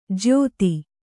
♪ jyōti